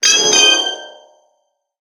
Cri de Méga-Mélodelfe dans Pokémon HOME.
Cri_0036_Méga_HOME.ogg